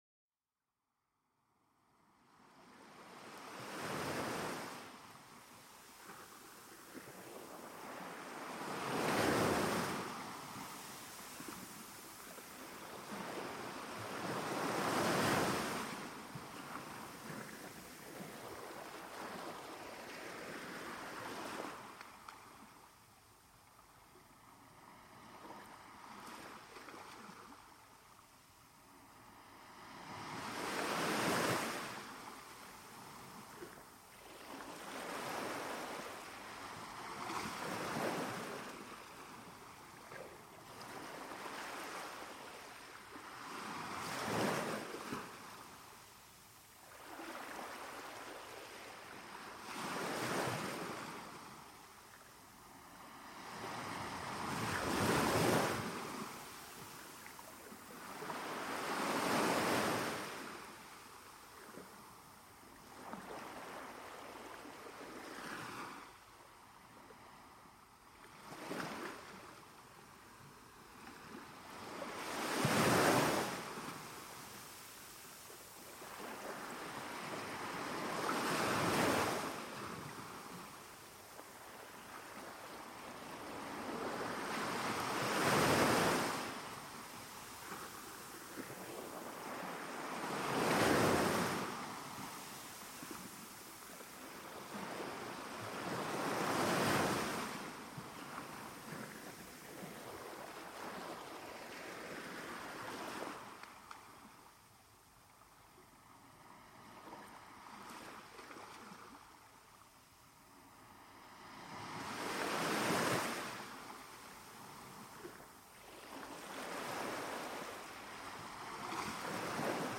Le son des vagues pour une relaxation profonde